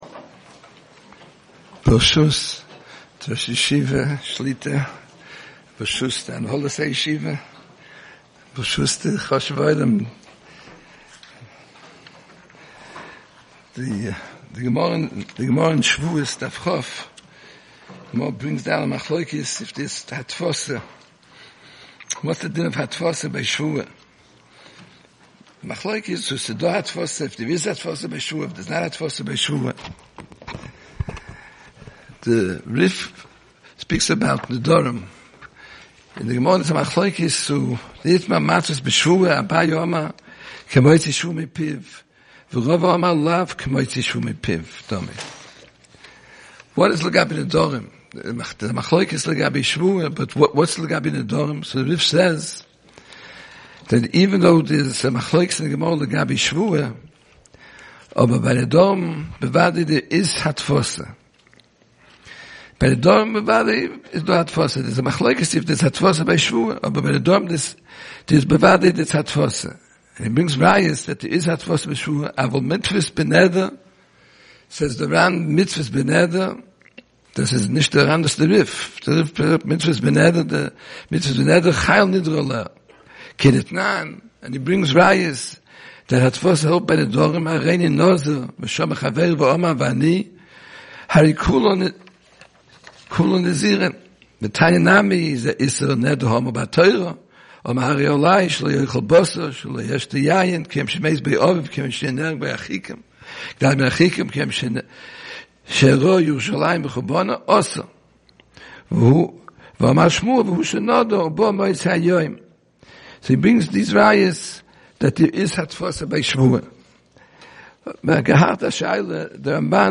Special Lecture - Ner Israel Rabbinical College